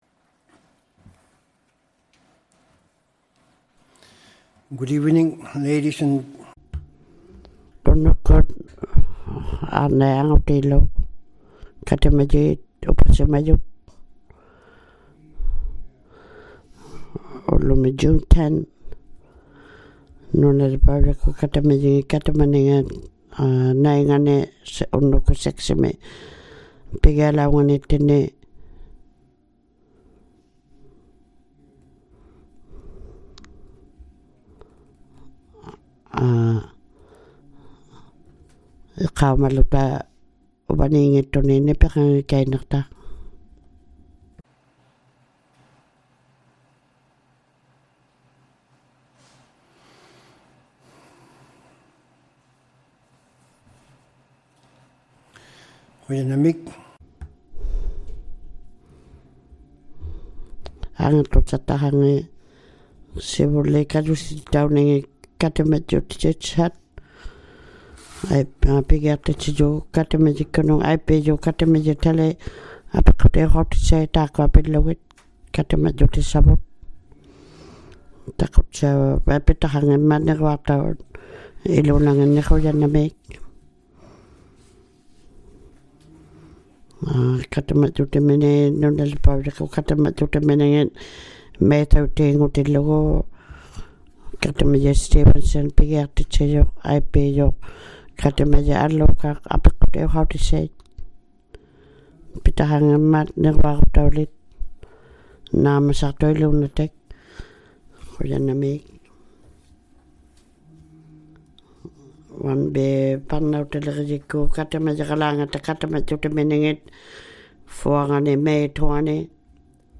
ᓄᓇᓕᐸᐅᔭᒃᑯᑦ ᑲᑎᒪᔨᖏᑕ ᑲᑎᒪᓂᖓᑦ # 09 - City Council Meeting # 09 | City of Iqaluit